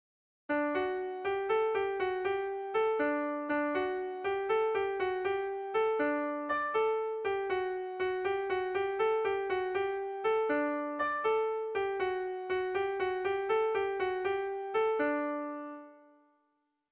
Gregorianoa
Hiru puntuko berdina, 8 silabaz
ABDE